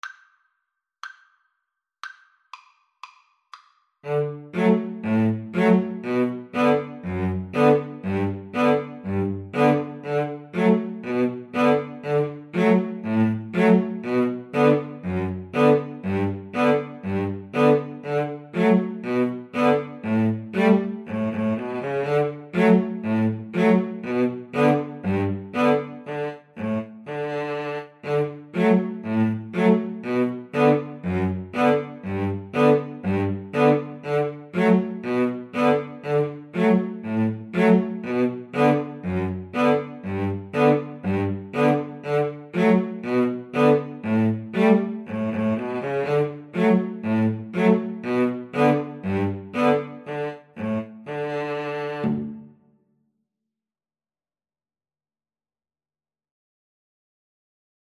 Free Sheet music for Violin-Cello Duet
D major (Sounding Pitch) (View more D major Music for Violin-Cello Duet )
2/2 (View more 2/2 Music)
Allegro =c.120 (View more music marked Allegro)
Traditional (View more Traditional Violin-Cello Duet Music)
Irish